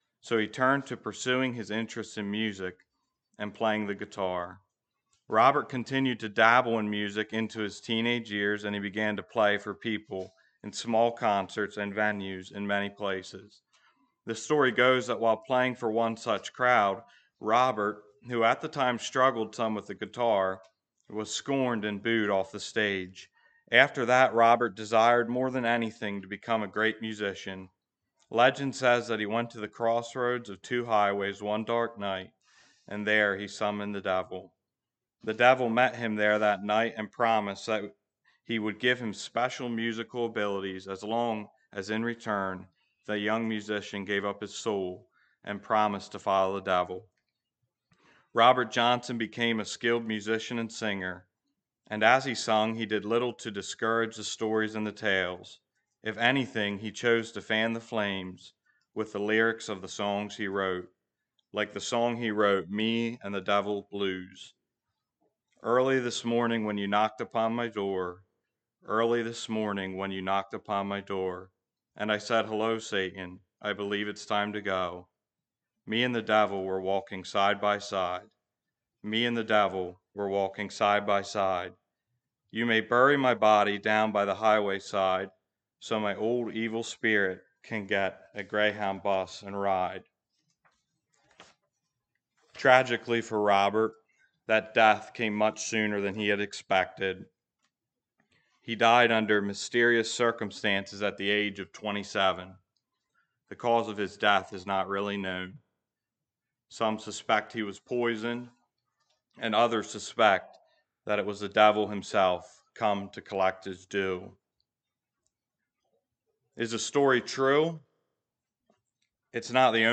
Listen to sermon recordings from Word of Life Mennonite Fellowship.